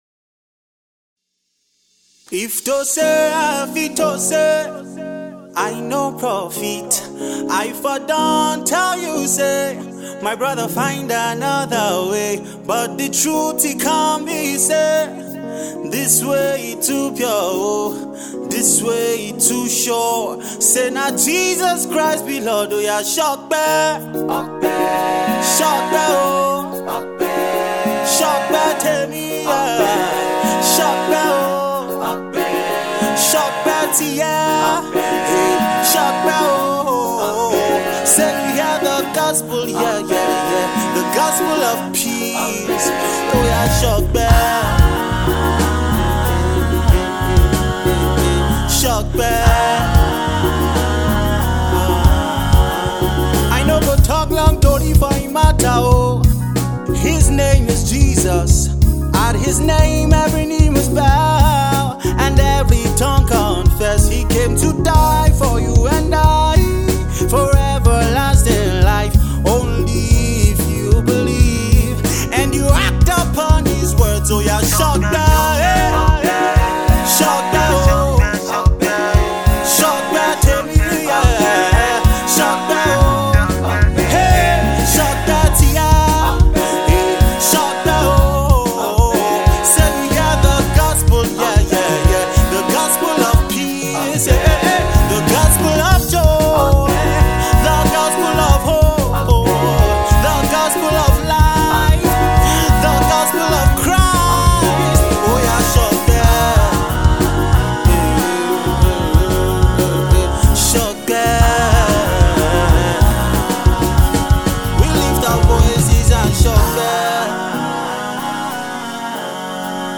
Versatile Christian singer and songwriter